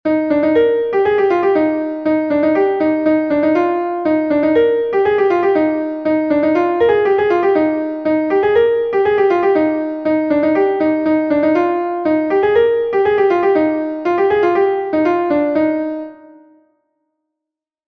Ton Bale Lokunole is a Bale from Brittany